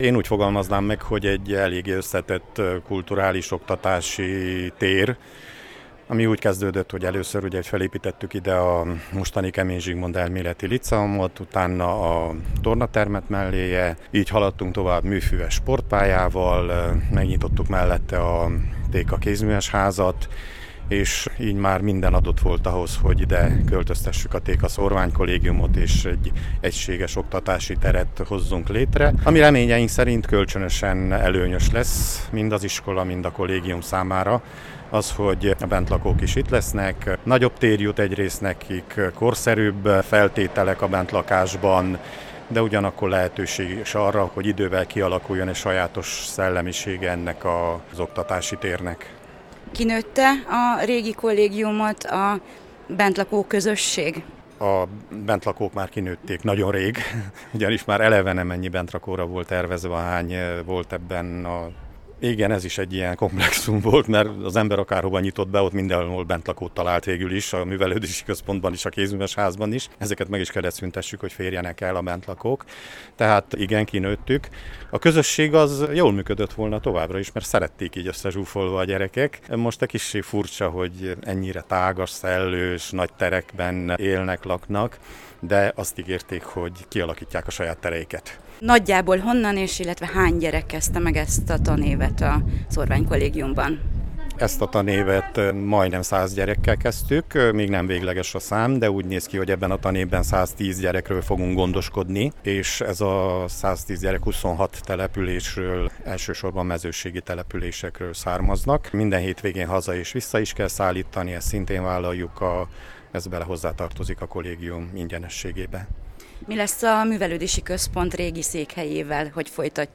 Felavatták vasárnap a szamosújvári Téka Szórványkollégium új épületszárnyát. Az ünnepségen jártunk.